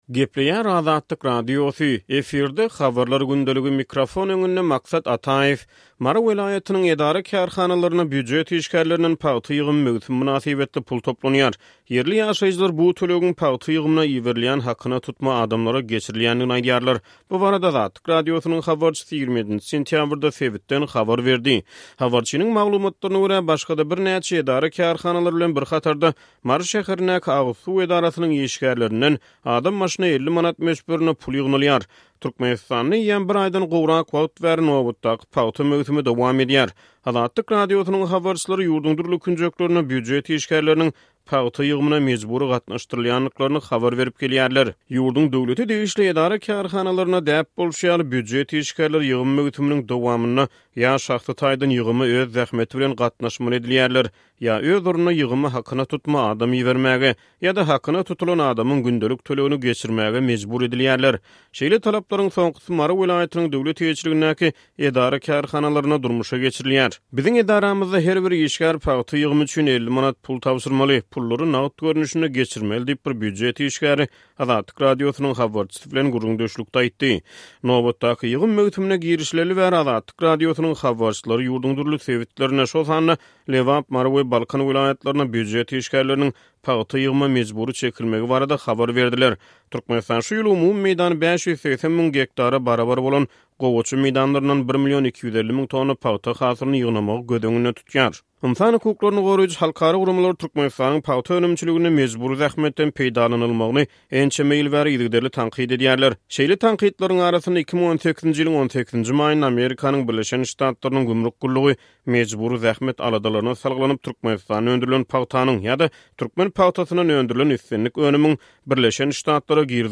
Mary welaýatynyň edara-kärhanalarynda býujet işgärlerinden pagta ýygym möwsümi mynasybetli pul toplanýar. Ýerli ýaşaýjylar bu tölegiň pagta ýygymyna iberilýän hakyna tutma adamlara geçirilýändigini aýdýarlar. Bu barada Azatlyk Radiosynyň habarçysy 27-nji sentýabrda sebitden habar berdi.